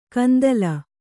♪ kandala